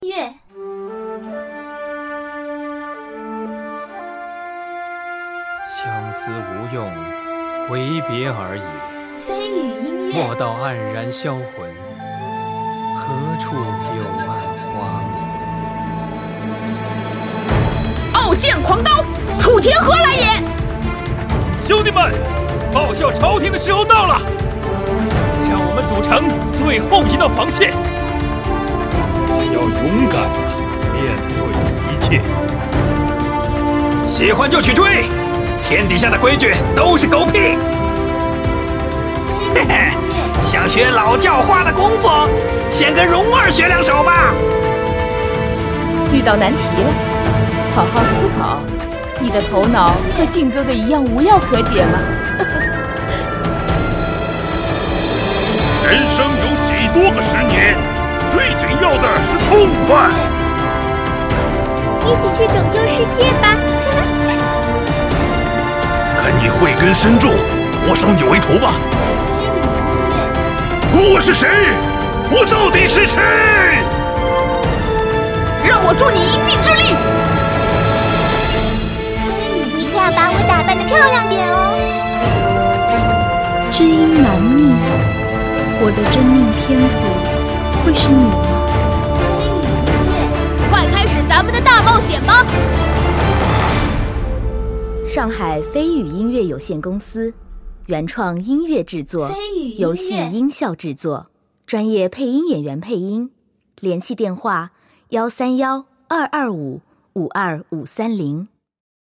游戏中大量对白配音都得益于国内专业级游戏音乐公司绯雨音乐的配音演员亲情演绎。